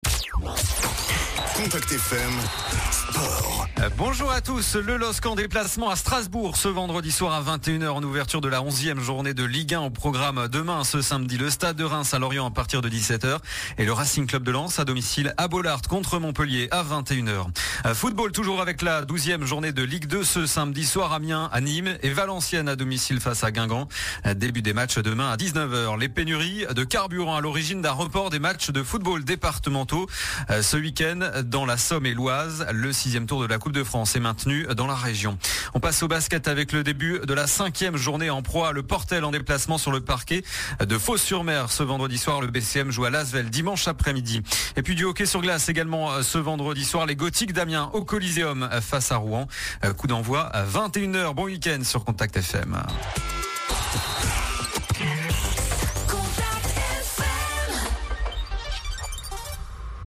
Le journal des sports du vendredi 14 octobre